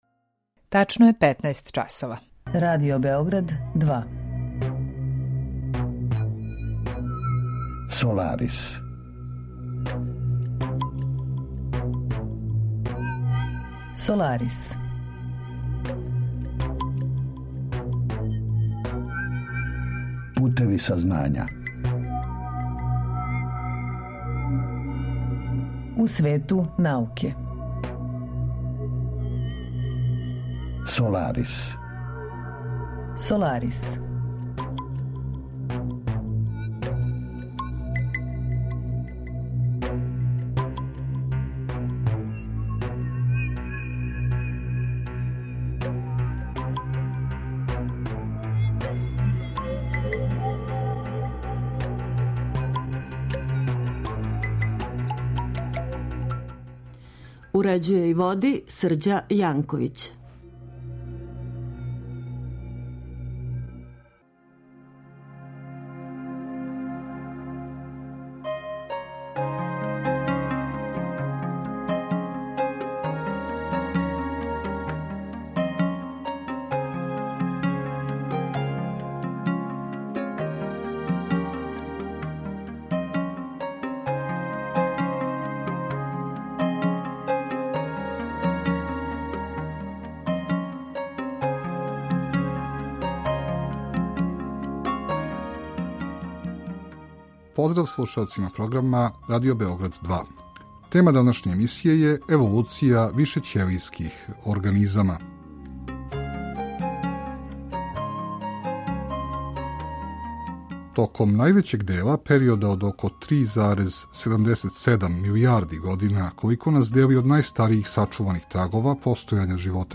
Саговорник